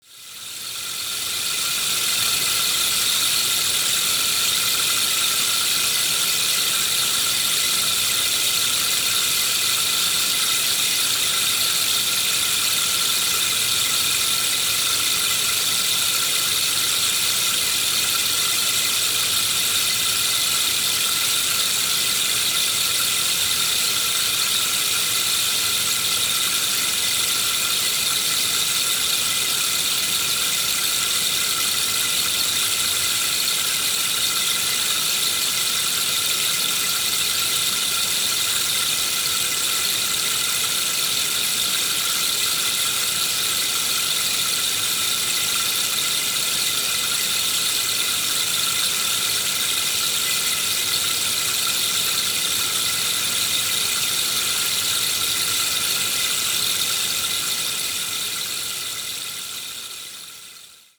Its gentle, cascading tones make it the perfect sound backdrop for sound baths, sound journeys, yoga sessions, and meditation, allowing you to play other instruments simultaneously.